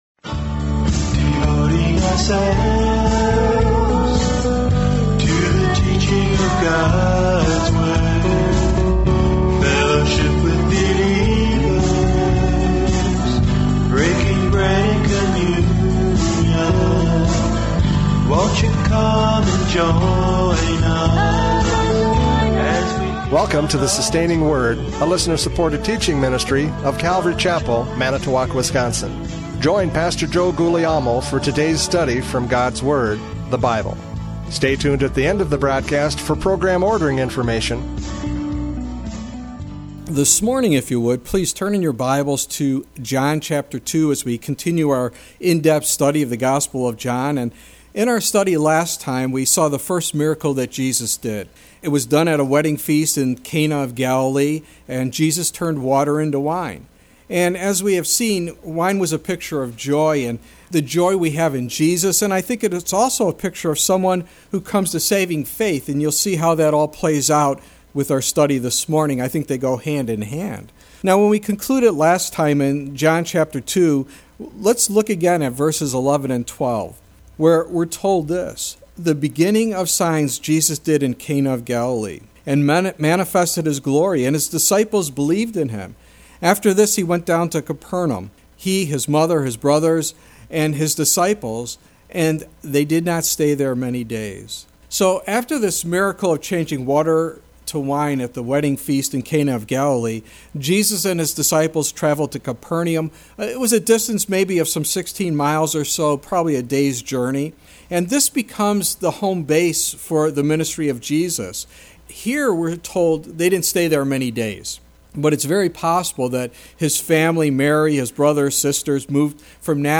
John 2:13-17 Service Type: Radio Programs « John 2:1-12 Joy in Jesus!